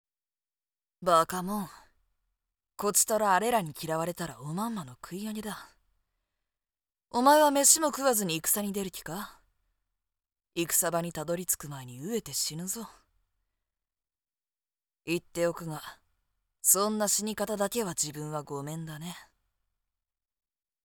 【サンプルセリフ】
（この猫っかぶり、ずっりーよ！のツッコミに、クーーールに）